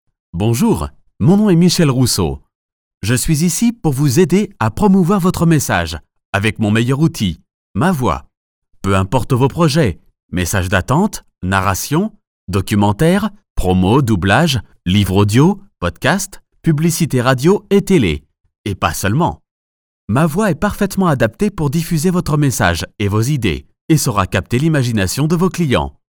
He owns his home studio with ISDN and Neumann microphone His voice and studio are accredited by SaVoa (Society of Accredited Voice Over Artists). His style can be : convincing, reassuring, dynamic, soft, sensitive, elegant, Warm.
Sprechprobe: Sonstiges (Muttersprache):